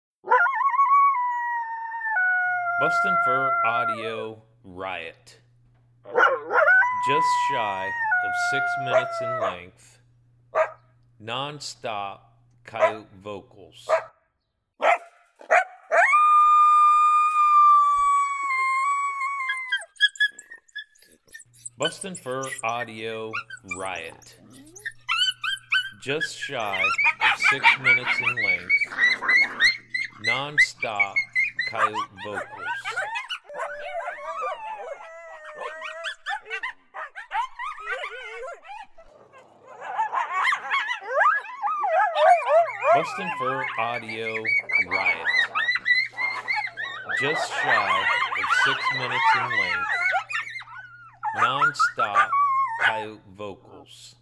Just under 6 minutes of nonstop Coyote vocalizations that drive Coyotes wild! It has howls, social vocals, fighting, barking, yips and squeals.